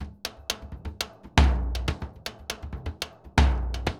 Bombo_Salsa 120_2.wav